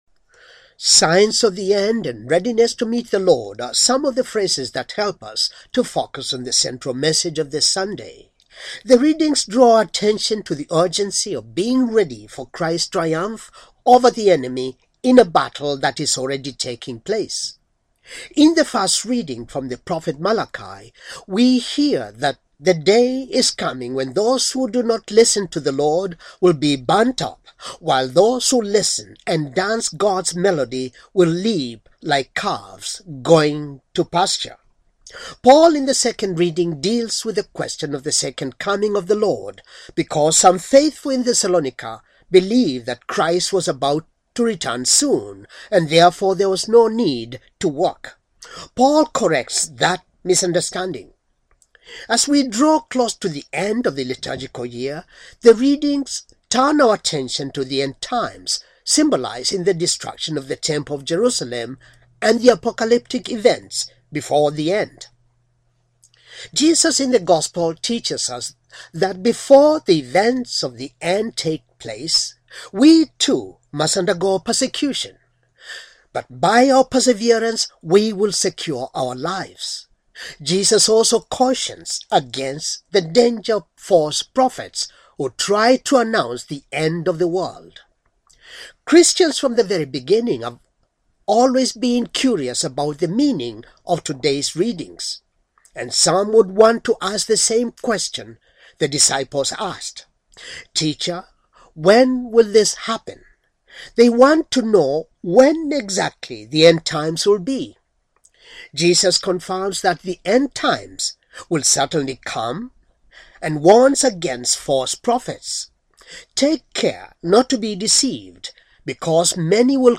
Homily, thirty third, Sunday, ordinary, time, year c, religion, catholic,